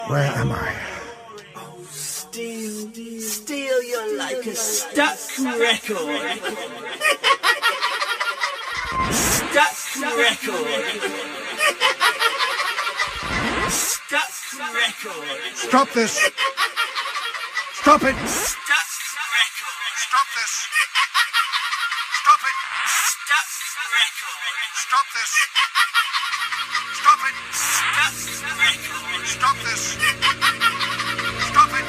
Audio drama
Several of the characters in this story are Americans, but the actors' accents aren't very convincing...they still sound British!
Memorable Dialog